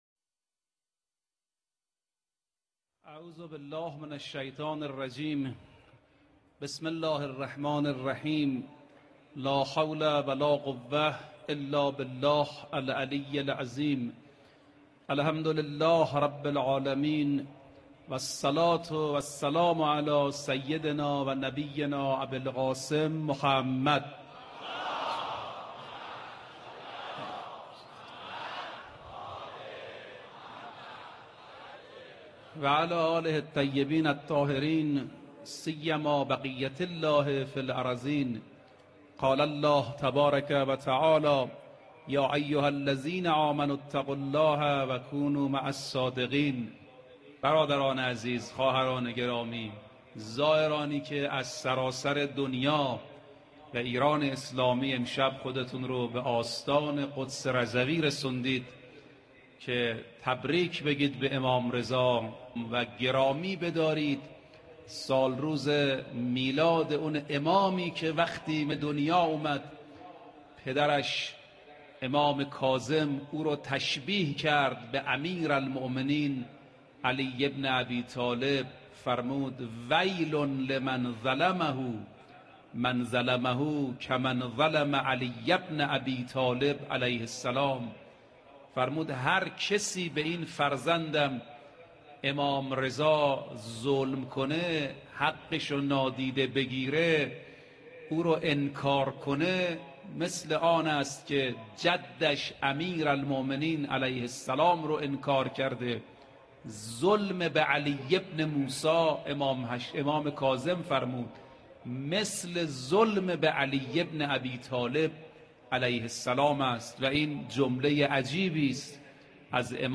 سخنرانی به مناسبت میلاد امام رضا علیه السلام